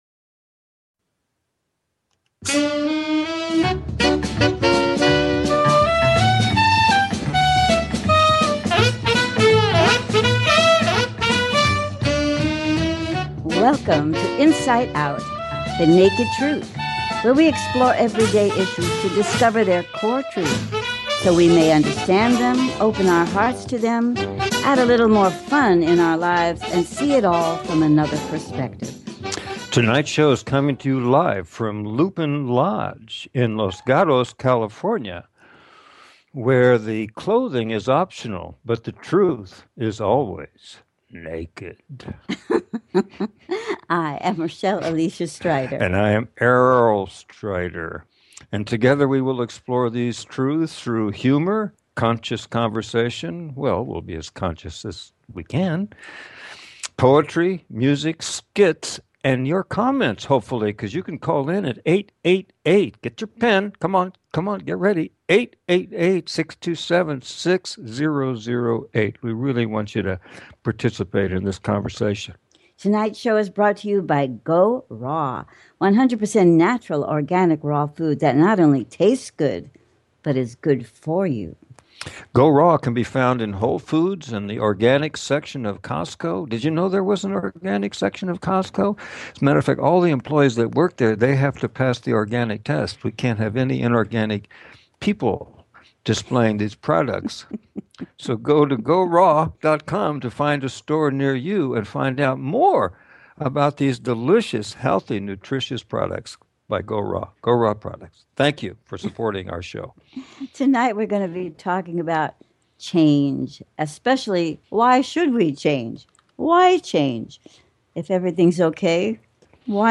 In each show we try to integrate ideas with music, art, humor and conscious conversation.